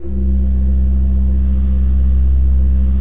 Index of /90_sSampleCDs/Propeller Island - Cathedral Organ/Partition H/KOPPELFLUT M